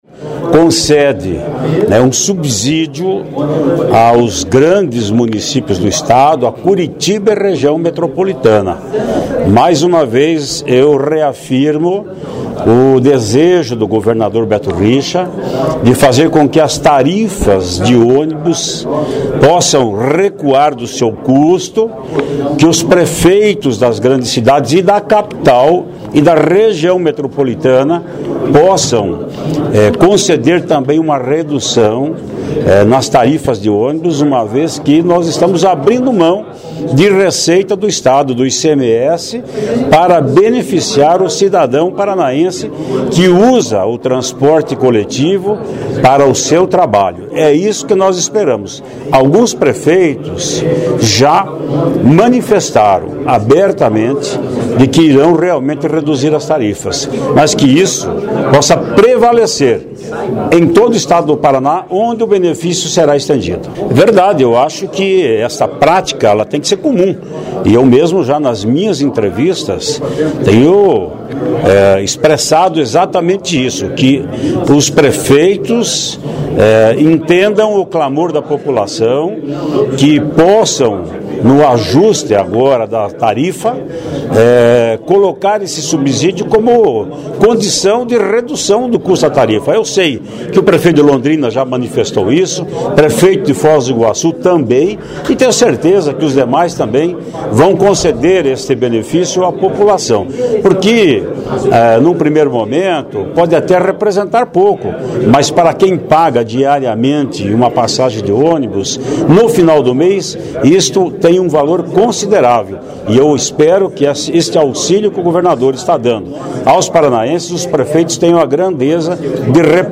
Sonora do deputado Ademar Traiano (PSDB), líder do Governo na ALEP, sobre a aprovação – nesta terça-feira - do substitutivo para o projeto de isenção do ICMS sobre o diesel do transporte coletivo em cidades com mais de 150 mil habitantes